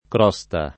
kr0Sta] s. f. — sim. il cogn. C. — pn. originaria con -o- chiuso (-u- breve del lat. crusta), conservata nella maggior parte dell’Alta Italia e in qualche altra regione, ma scomparsa o quasi nell’uso della Tosc. e della rimanente It. centr. fin dal tempo delle più antiche attestazioni — cfr. crogiolare